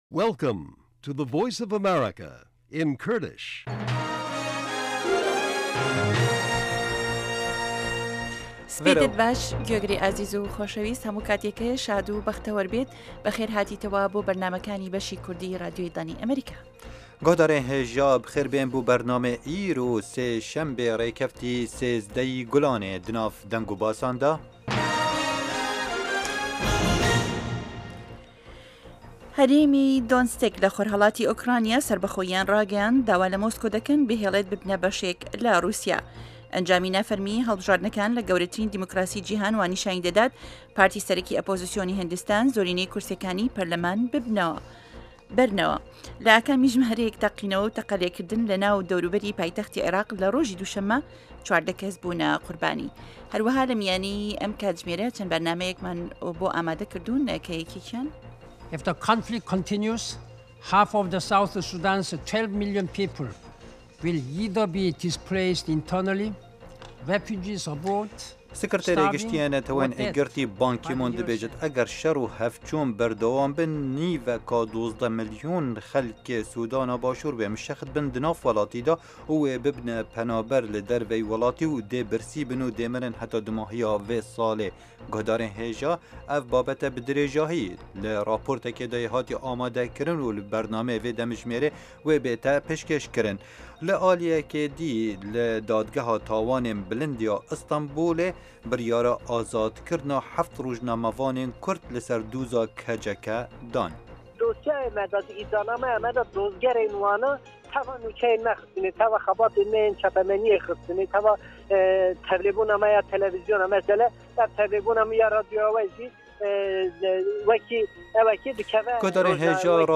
بابه‌ته‌كانی ئه‌م كاتژمێره بریتین له هه‌واڵه‌كان، ڕاپۆرتی په‌یامنێران، گفتوگۆ و شیكردنه‌وه، ئه‌مڕۆ له مێژووی ئه‌مه‌ریكادا، هه‌روههاش بابهتی ههمهجۆری هونهری، زانستی و تهندروستی، ئابوری، گهشتێك به نێو ڕۆژنامه جیهانییهكاندا، دیدوبۆچونی واشنتۆن، گۆرانی كوردی و ئهمهریكی و بهرنامهی ئهستێره گهشهكان له ڕۆژانی ههینیدا.